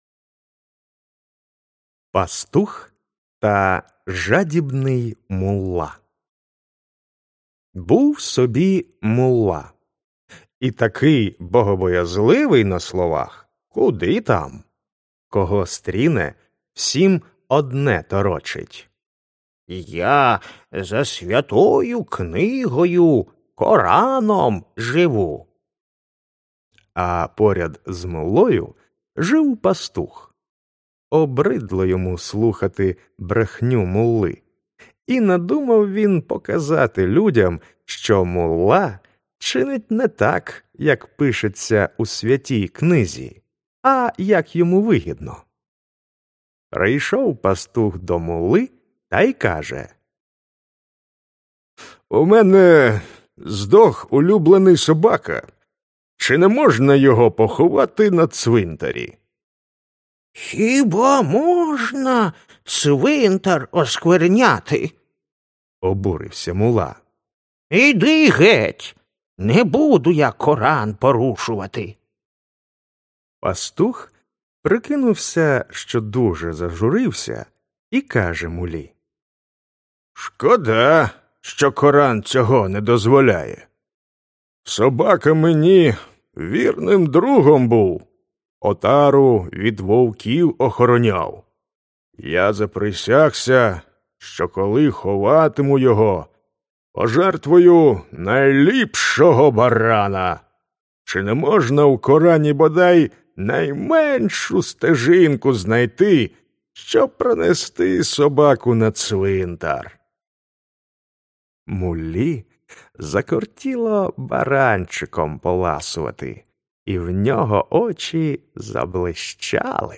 Аудіоказка Пастух та жадібний мулла
Жанр: Гумор / Навчання